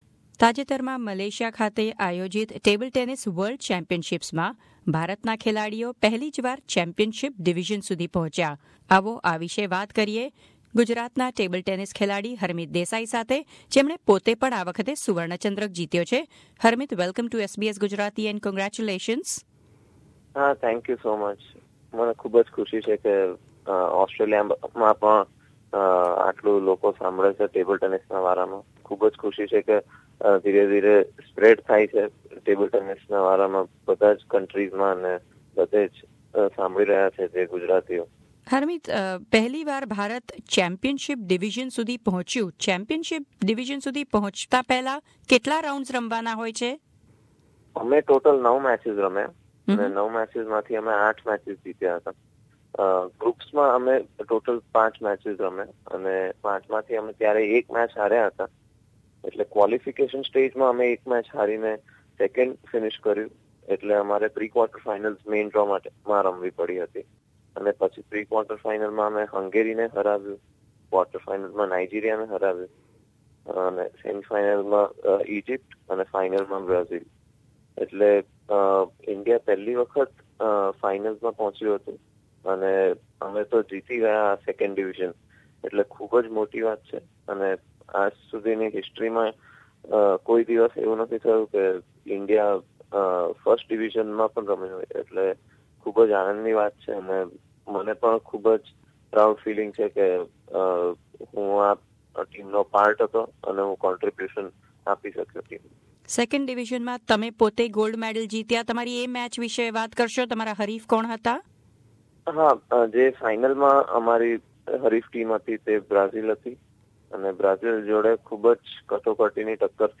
વાર્તાલાપ